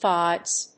音節fives 発音記号・読み方
/fάɪvz(米国英語)/